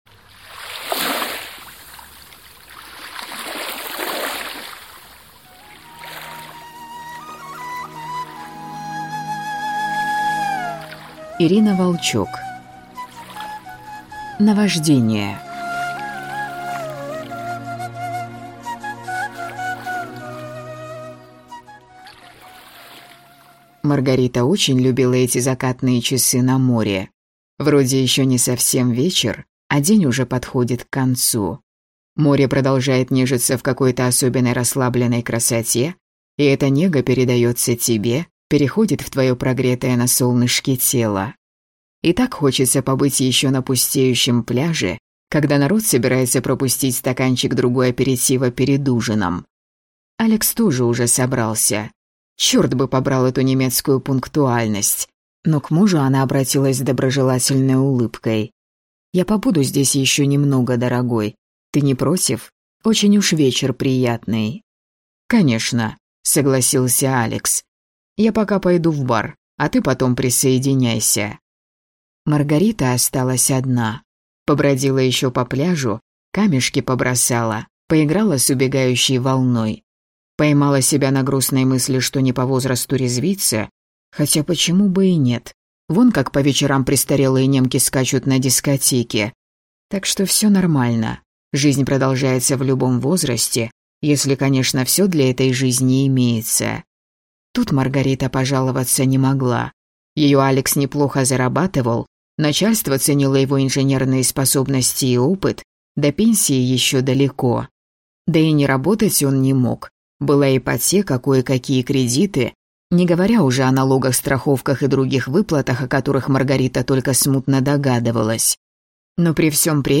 Аудиокнига Наваждение | Библиотека аудиокниг
Прослушать и бесплатно скачать фрагмент аудиокниги